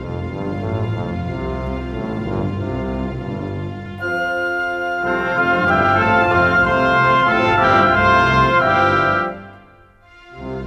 Rock-Pop 22.wav